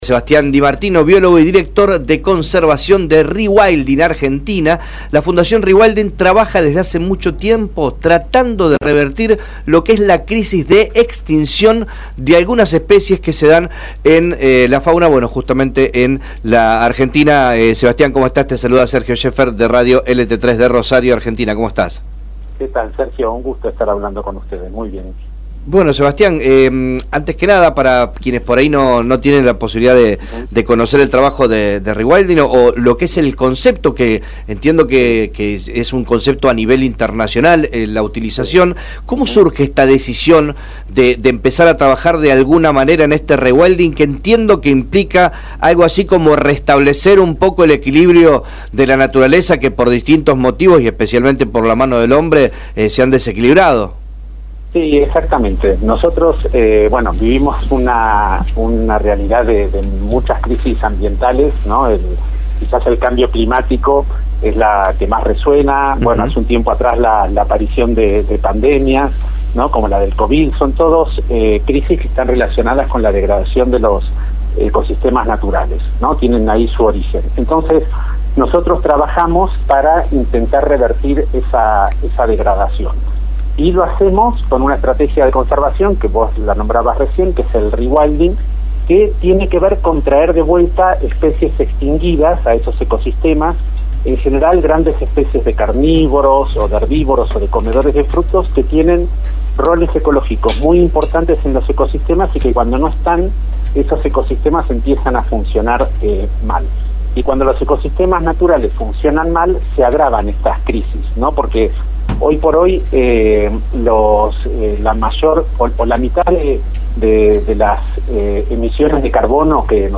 En diálogo con el programa Media Mañana